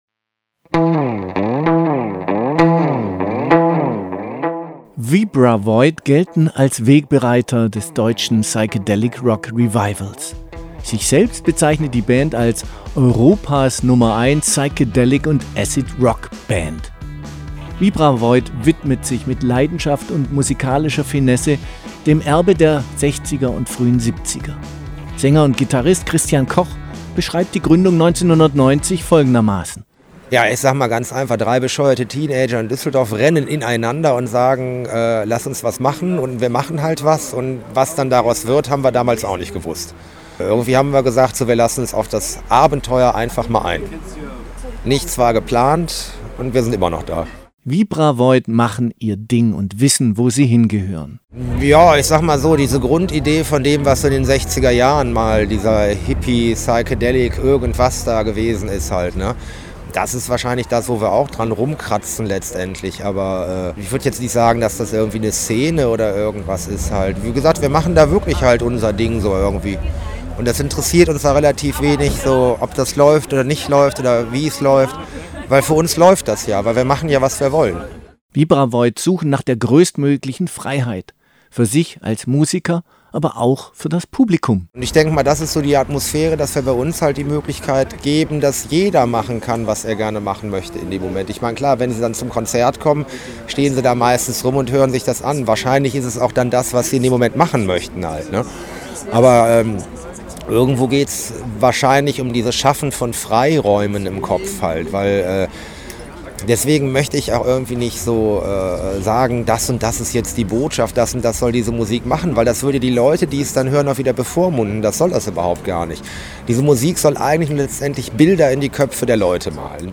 72004_Vibravoid_Int_final.mp3